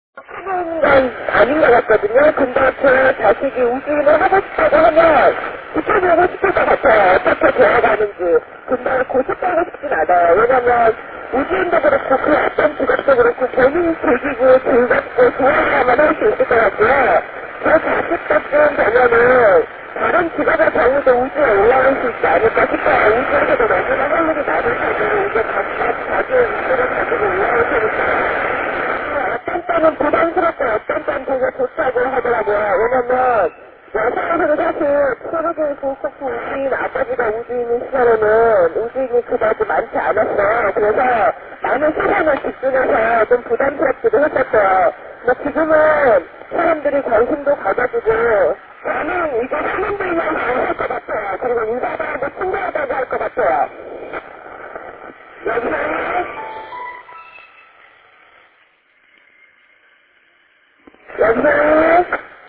122 Кб 13.04.2008 14:19 Разговор Корейского цлена экипажа МКС.